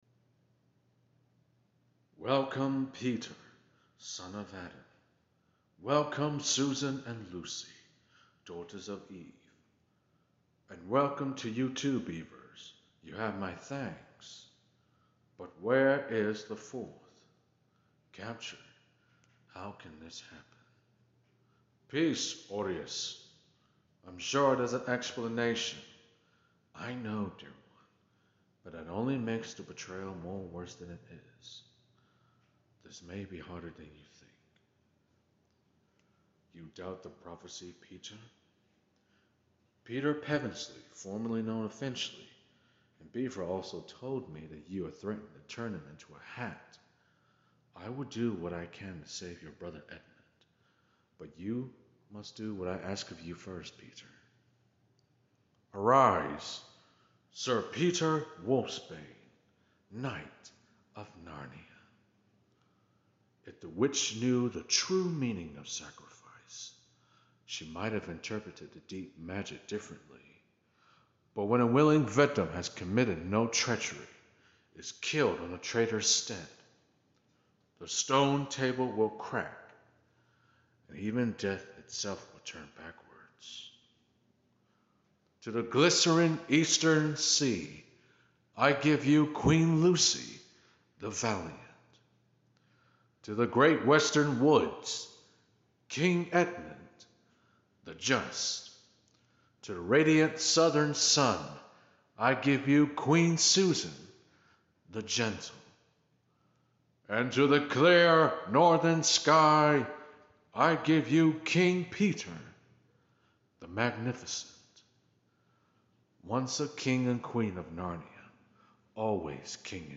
Voice impression of Aslan King sound effects free download